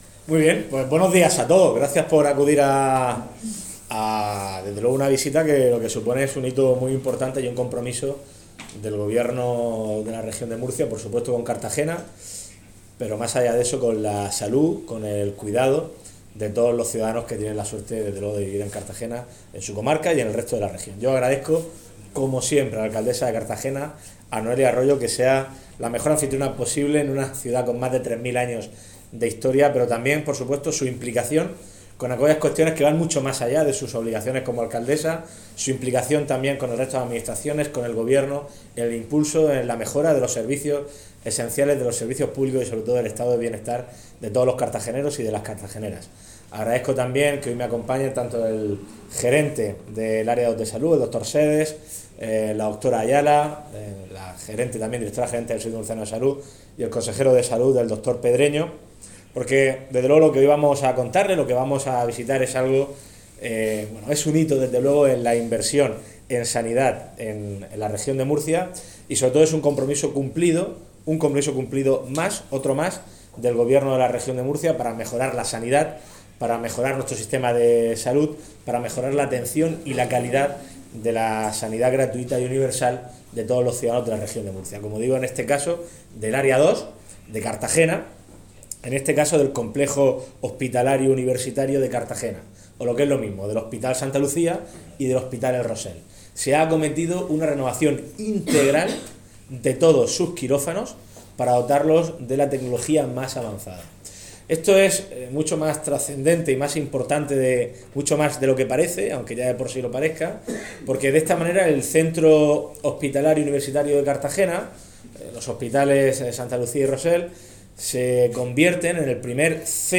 Enlace a Declaraciones de Fernando López Miras